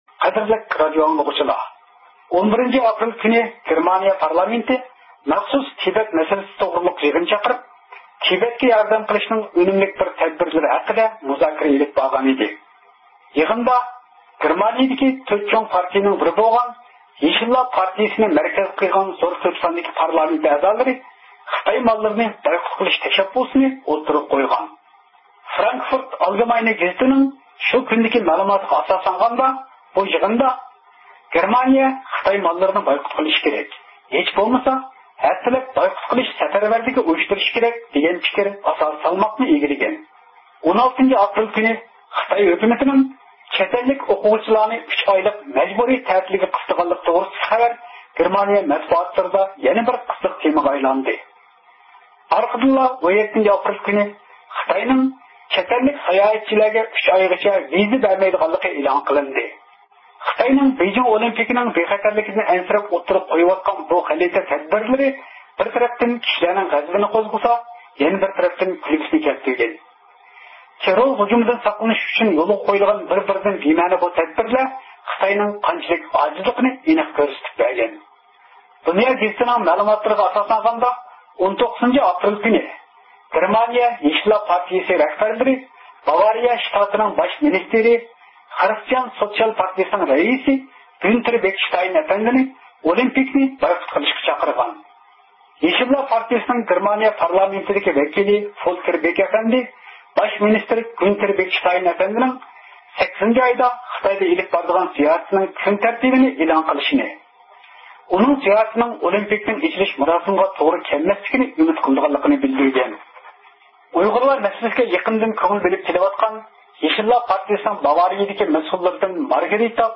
مۇخبىرىمىز